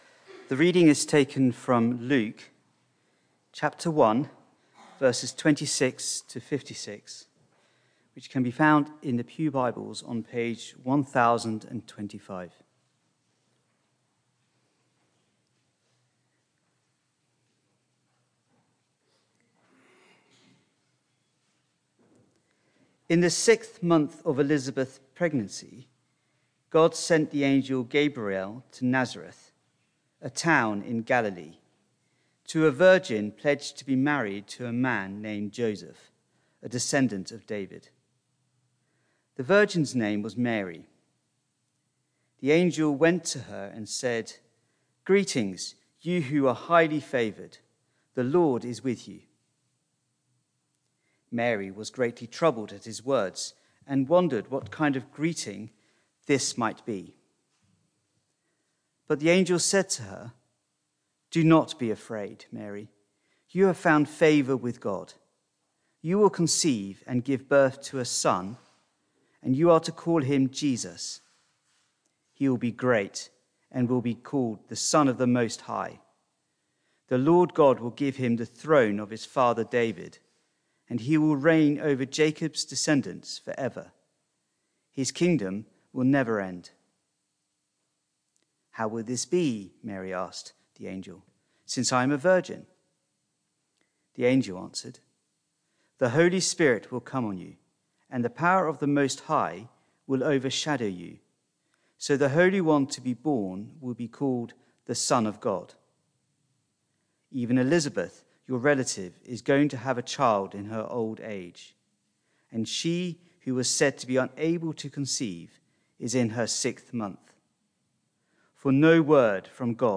Barkham Morning Service
Sermon